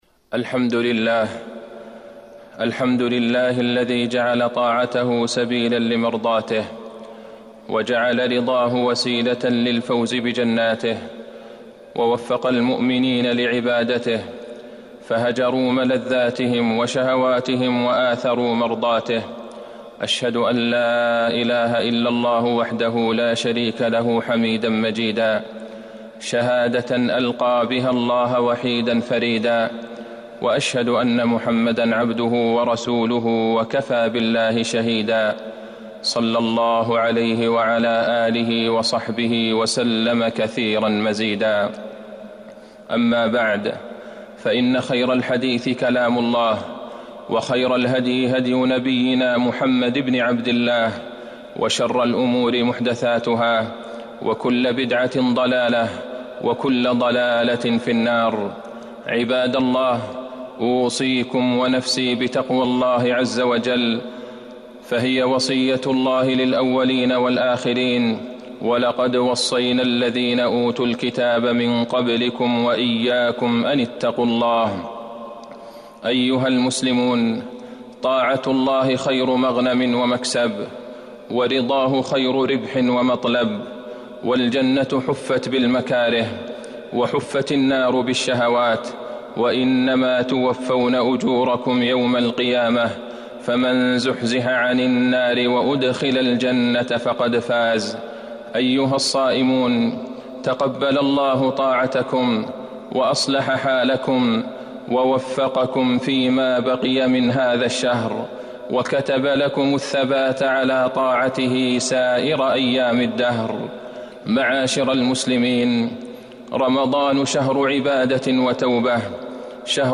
تاريخ النشر ٨ رمضان ١٤٤١ هـ المكان: المسجد النبوي الشيخ: فضيلة الشيخ د. عبدالله بن عبدالرحمن البعيجان فضيلة الشيخ د. عبدالله بن عبدالرحمن البعيجان فضل التهجد وقراءة القرآن